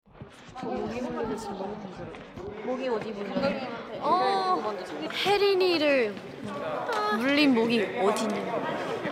Vocal Part
comp1_vocals_fd1d0a.mp3